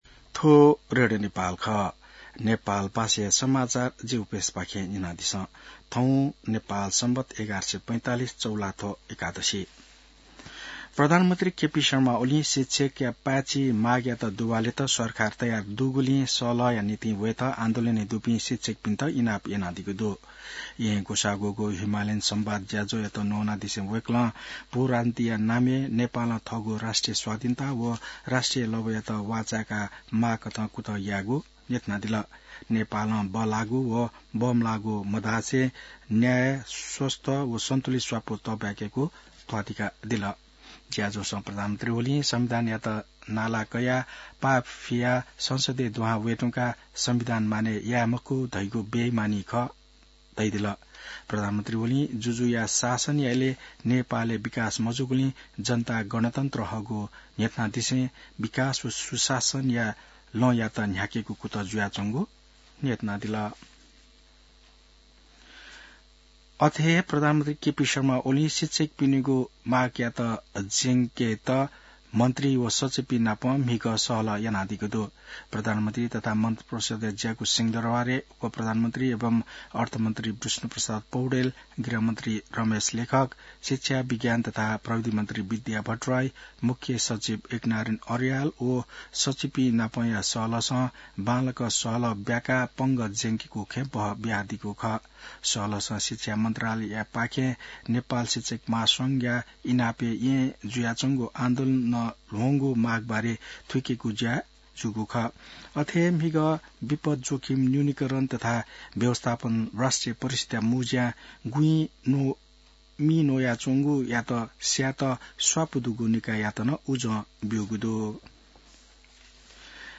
नेपाल भाषामा समाचार : २६ चैत , २०८१